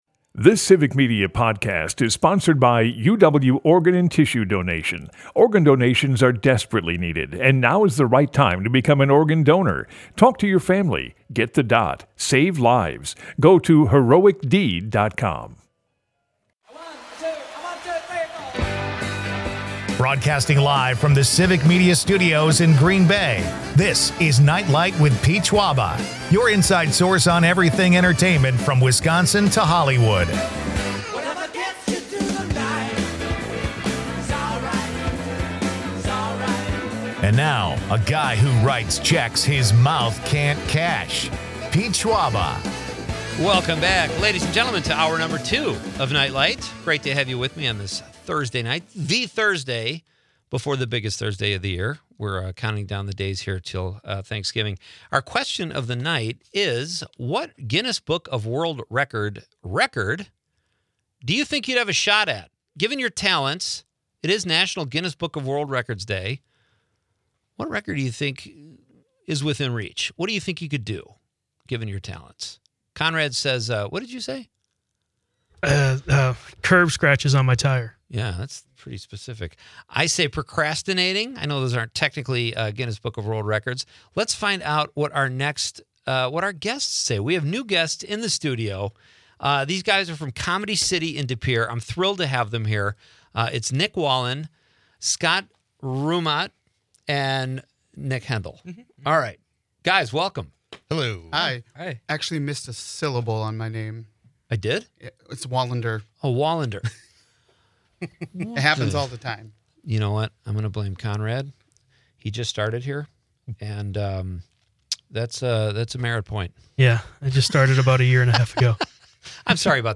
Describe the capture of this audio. and they all do some Improv in studio.